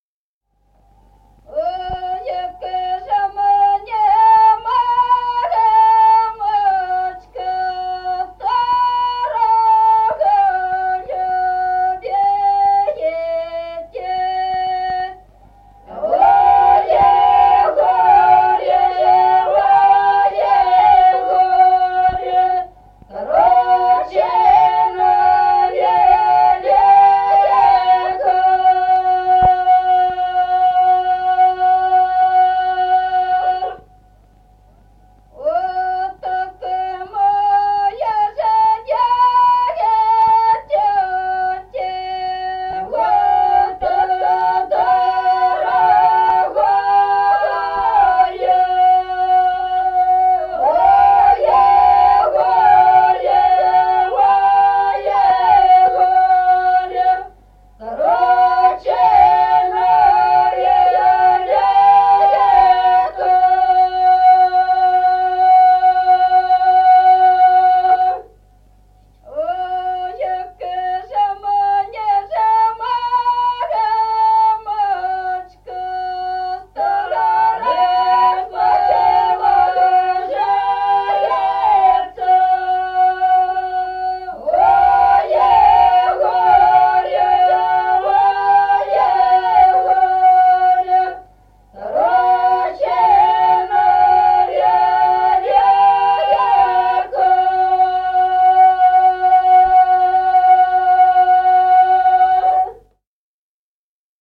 Песни села Остроглядово. Ой, як же мне, мамочка.